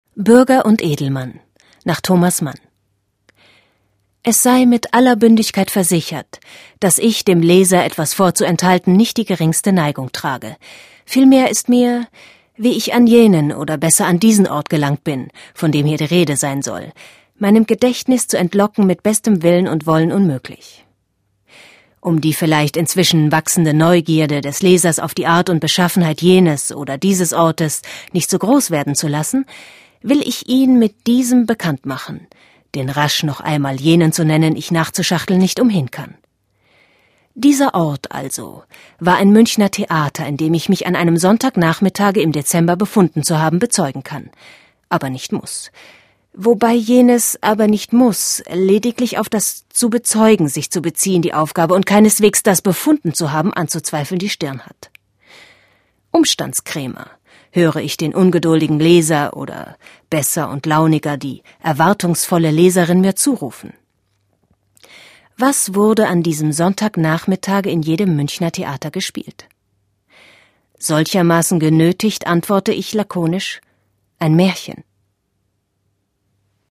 deutsche Sprecherin mit einer warmen, sinnlichen, kraftvollen, wandelbaren Stimme.
Sprechprobe: eLearning (Muttersprache):